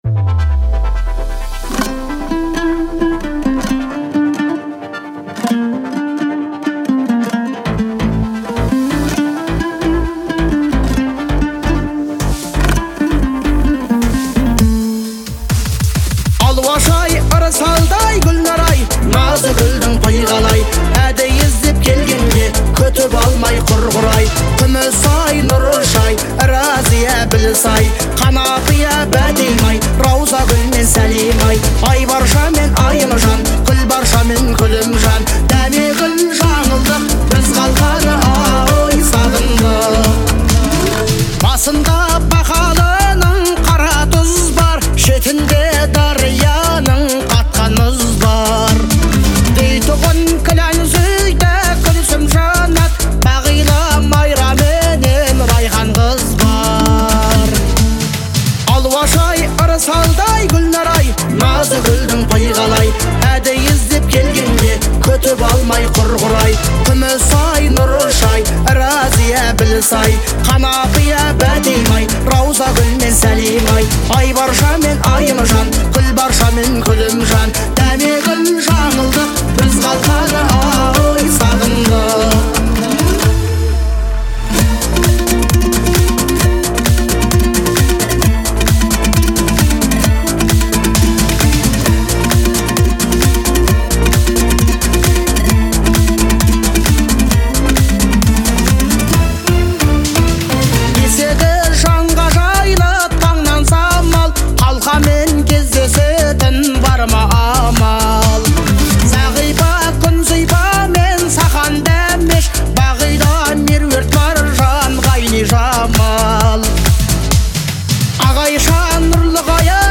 Звучание песни отличается мелодичностью